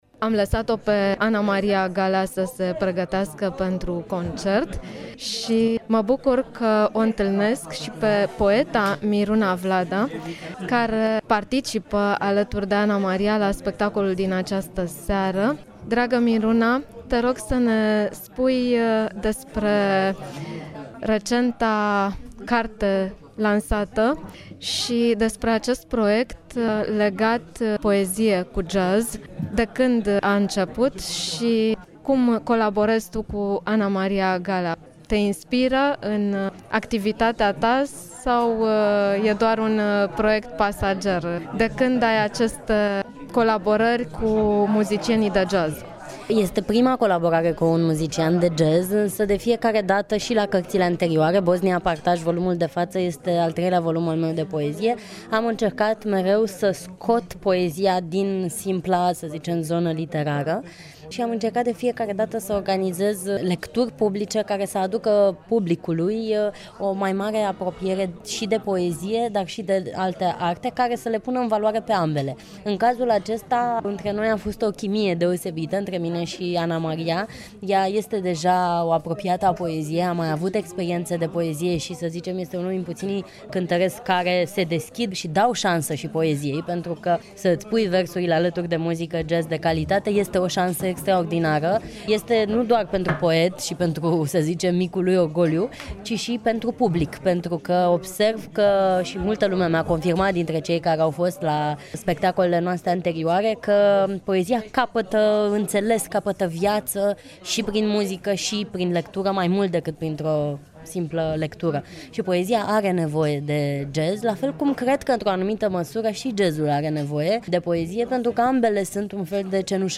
Secvență din spectacolul de la București – poezie și jazz: